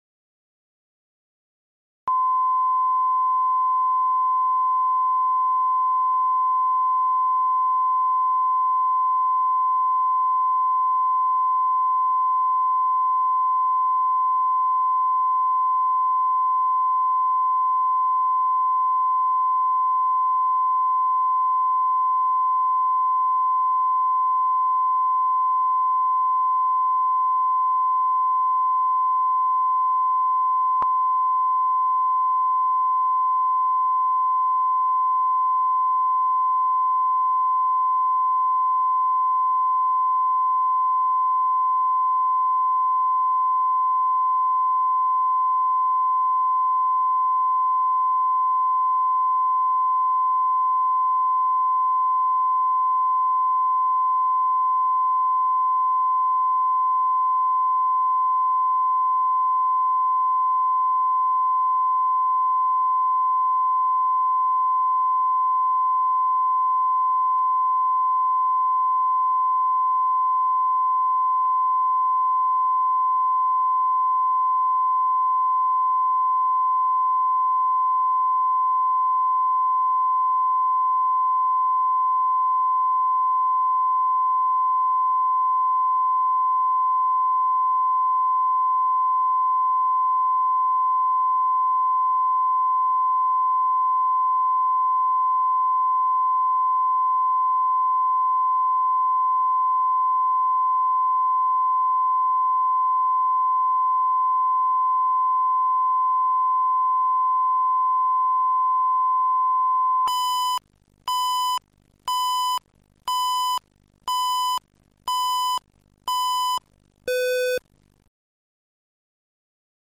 Аудиокнига Подарок для папы | Библиотека аудиокниг